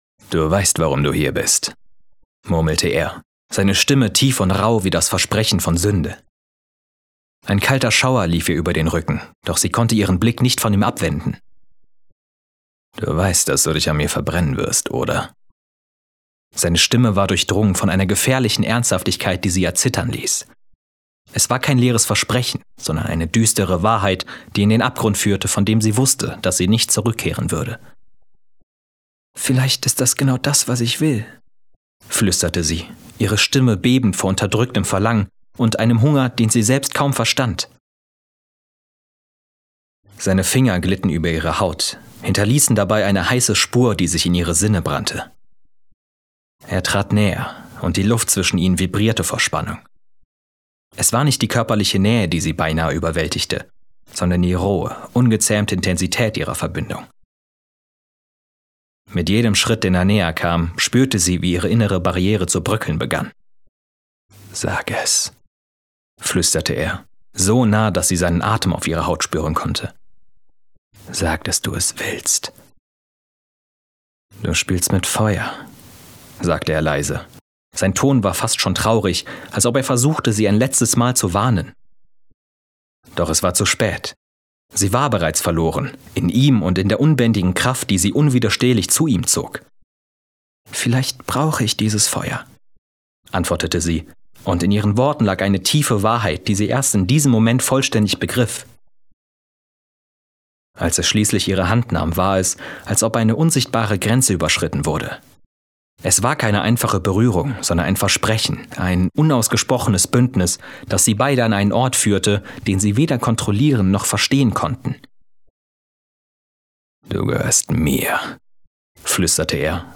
Moderation - WDR, laut, fröhlich (Demo) Düsterer Monolog
Spiel - Vorlesend mit rauer Stimme Spiel - Wut mit Schrei